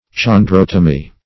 Search Result for " chondrotomy" : The Collaborative International Dictionary of English v.0.48: Chondrotomy \Chon*drot"o*my\, n. [Gr.